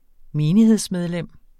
Udtale [ ˈmeːniheðs- ]